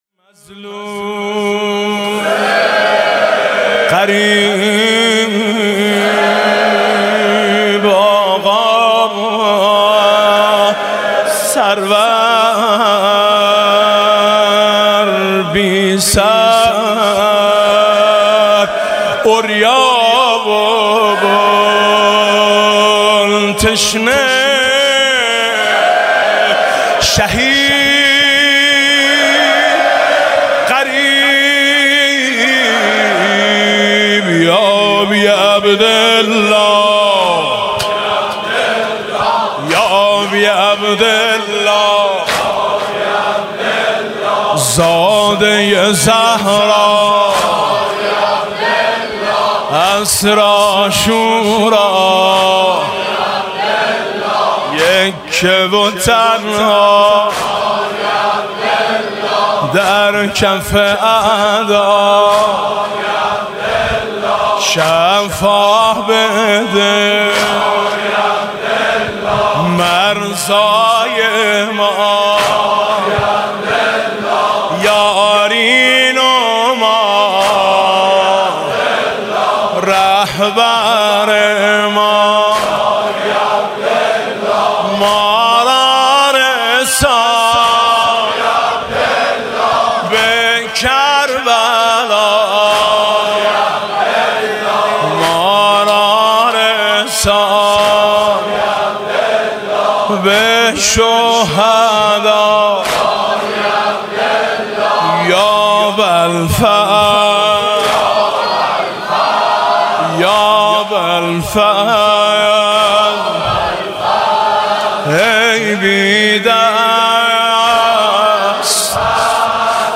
«محرم 1396» (شب پانزدهم) نوحه خوانی: مظلوم حسین